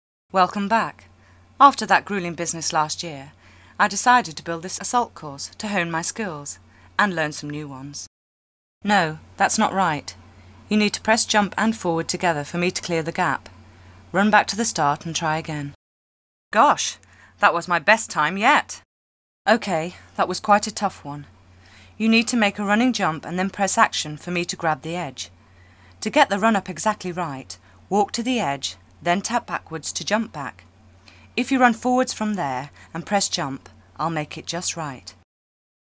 Голос Лары Крофт    0,84 MB